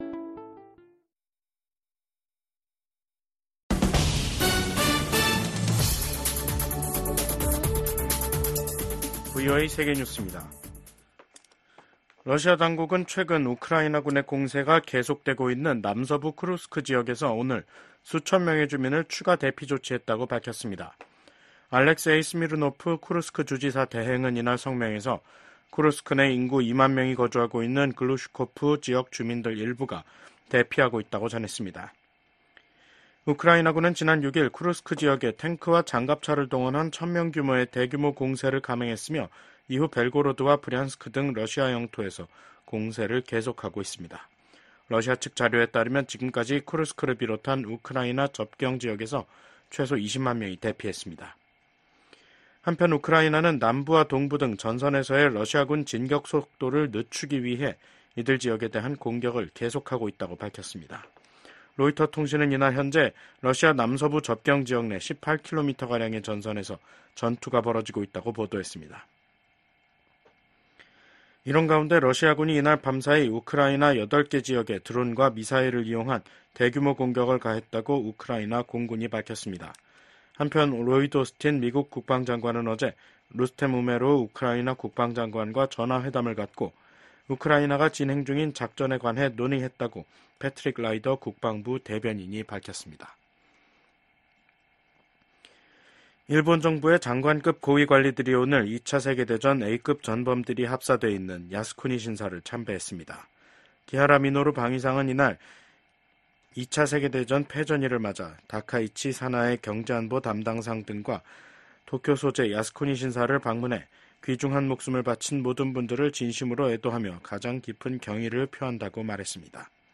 VOA 한국어 간판 뉴스 프로그램 '뉴스 투데이', 2024년 8월 15일 2부 방송입니다. 조 바이든 미국 대통령은 퇴임 의사를 밝힌 기시다 후미오 일본 총리가 미한일 협력 강화에 기여했다고 평가했습니다. 윤석열 한국 대통령은 광복절을 맞아 자유에 기반한 남북한 통일 구상과 전략을 ‘독트린’이라는 형식으로 발표했습니다. 북한이 2018년 넘긴 55개 유해 상자에서 지금까지 미군 93명의 신원을 확인했다고 미국 국방부 당국자가 말했습니다.